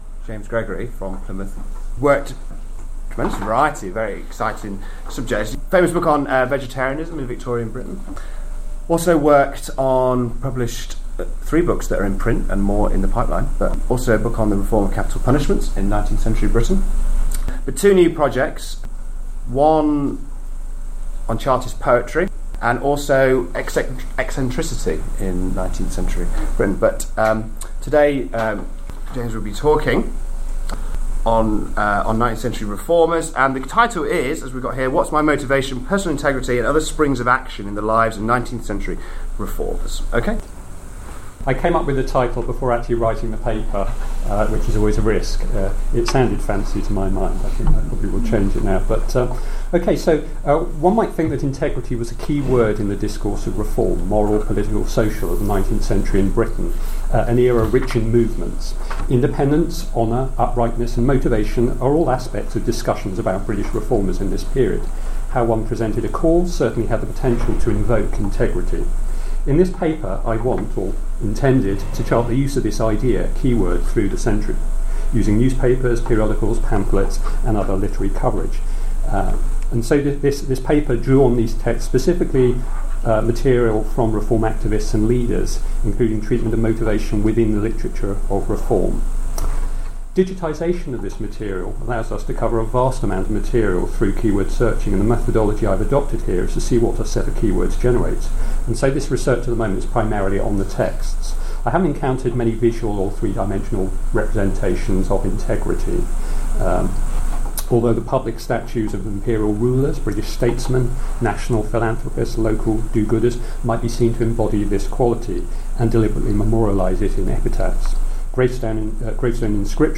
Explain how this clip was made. This talk was given at the Activism and Integrity workshop, December 2013